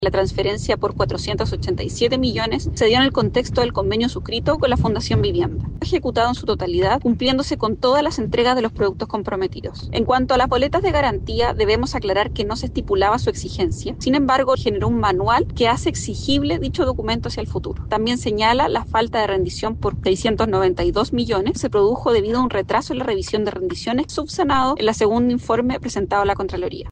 Belén Paredes, seremi de Vivienda y Urbanismo de Valparaíso, se refirió a la situación que afecta a su entidad, explicando que se cumplió con la entrega de los productos.